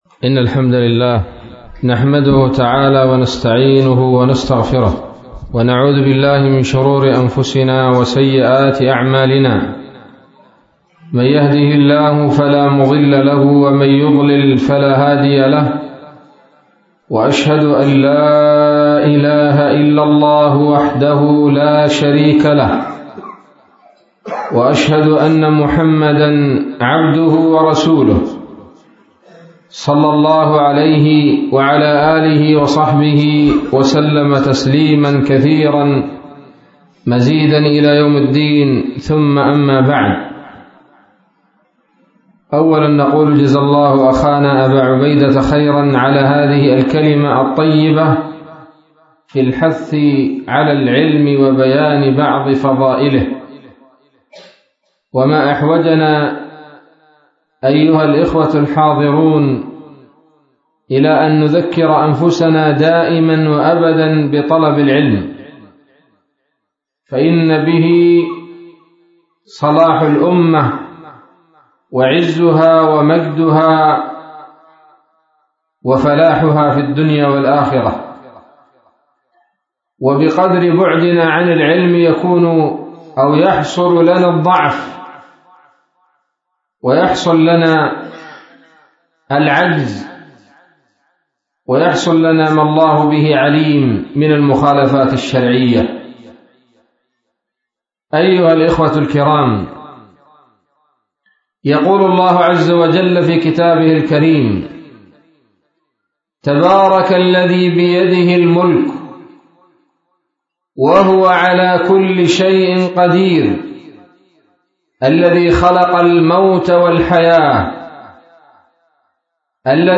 محاضرة قيمة بعنوان: ((التحذير من الفتن)) ليلة السبت 10 من شهر جمادى الأولى 1439هـ في مسجد النور - منطقة الجرباء بصبر